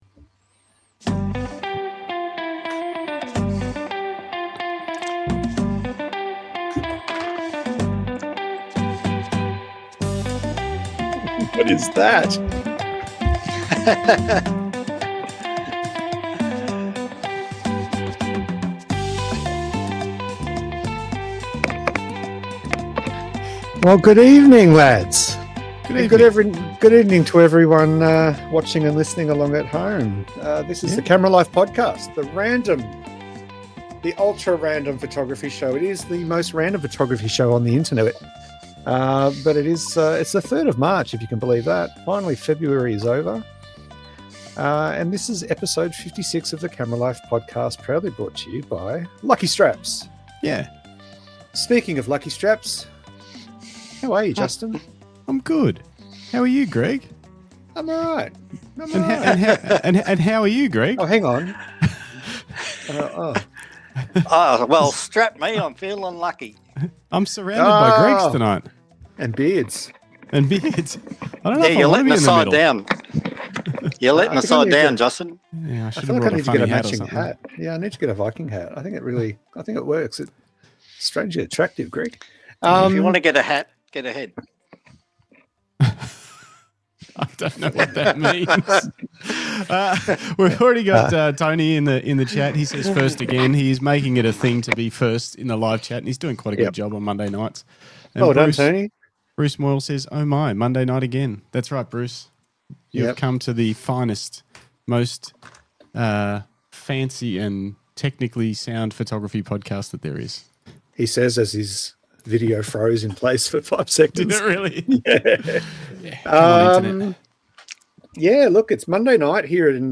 LIVE PHOTOGRAPHY PODCAST